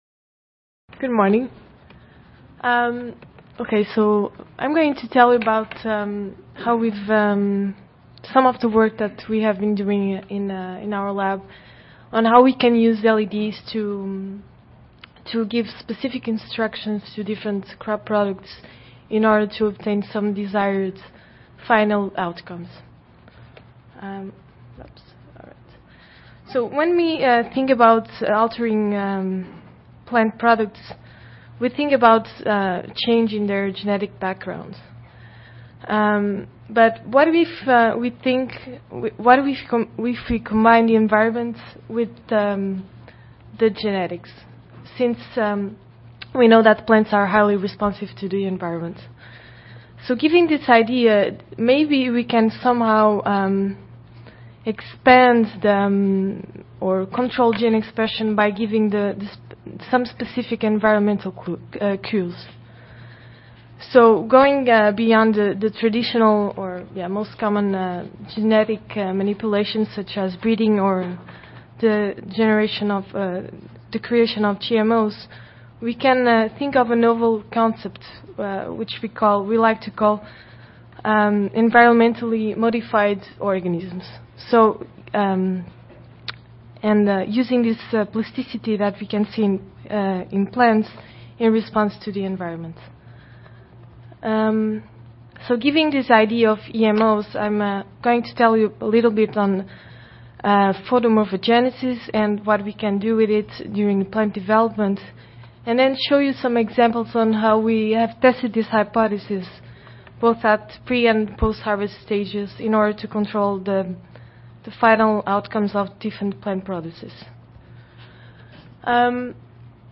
2014 ASHS Annual Conference: Colloquium: The Importance of Light Quality for High Value Plant Products *CEU Approved
University of Florida Audio File Recorded Presentation